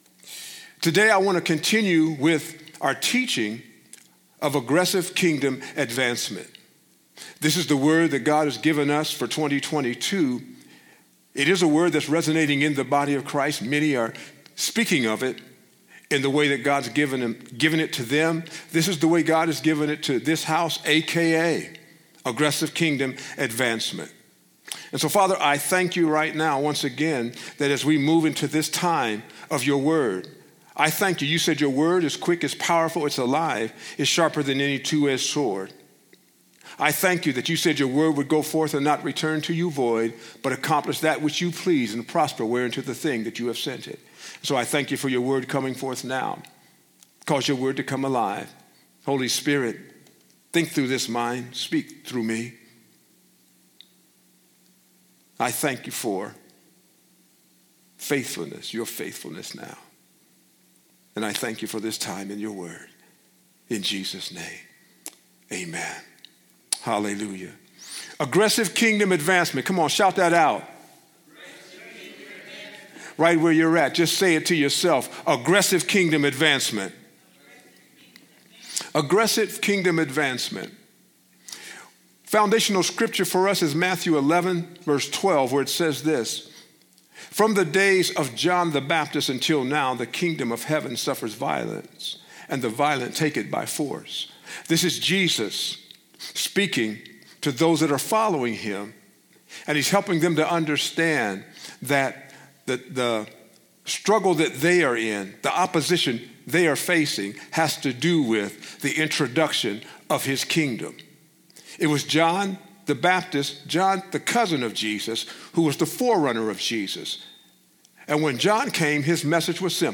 Teachings